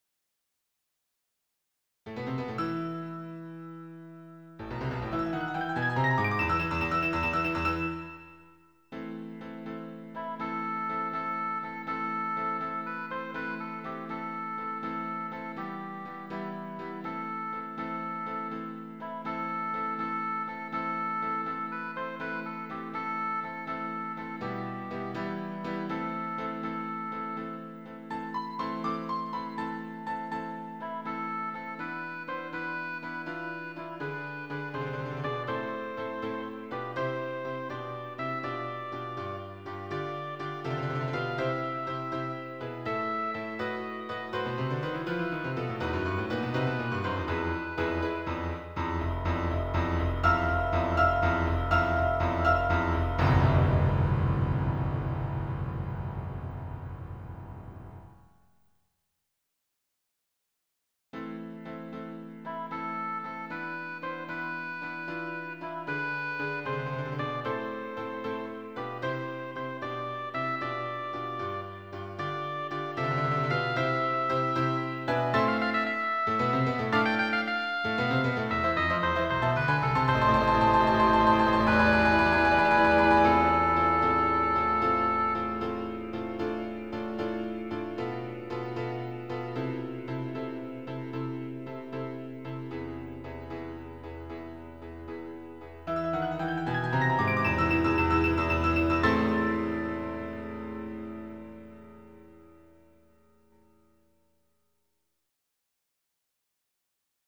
挿入歌２